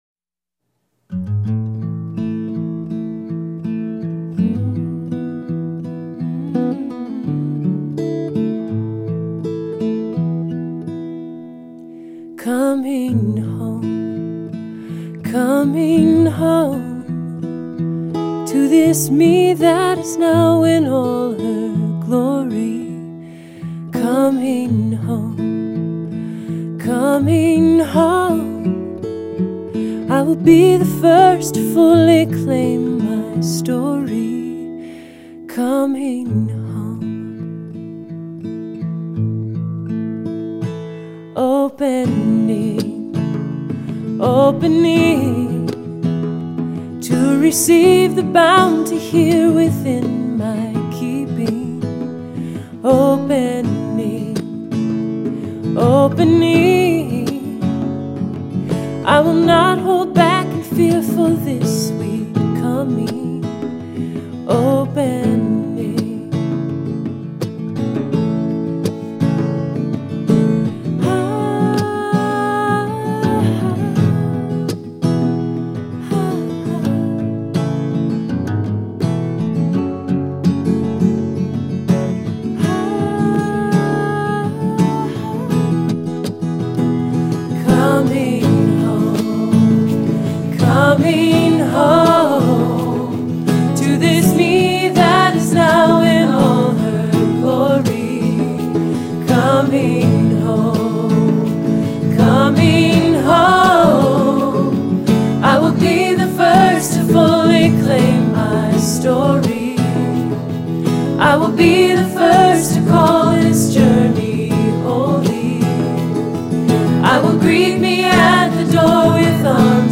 She has a beautiful voice.